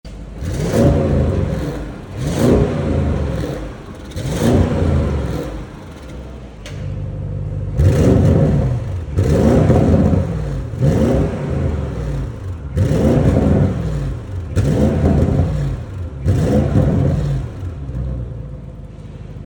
• 4.0L V8 TFSI Twin-Turbo Petrol Engine
Listen to the roar of the V8 twin turbo engine
RS6-GT-revs.mp3